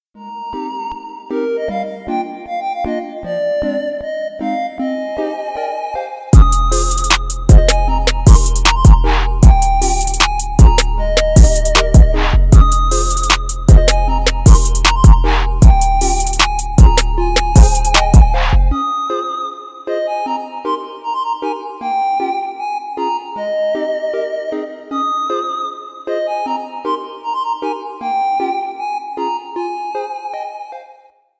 Ремикс # Электроника